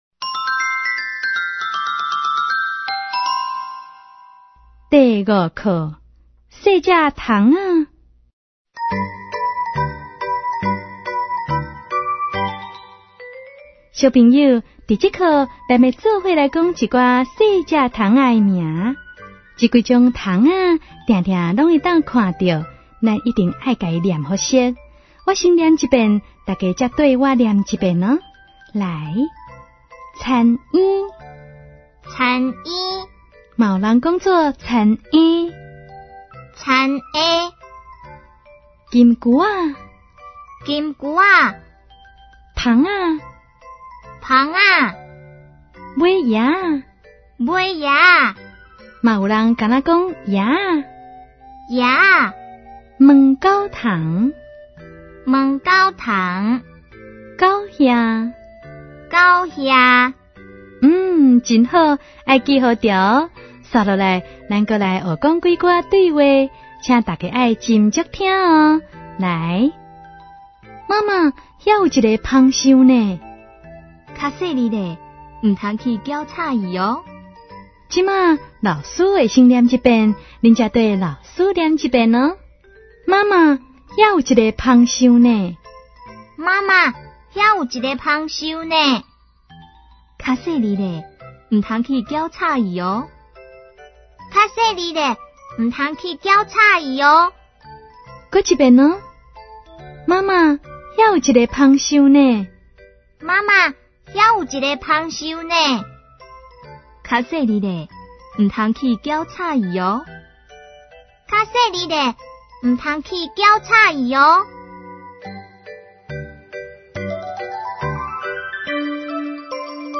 ● 兒歌唱遊、常用語詞、短句對話 ●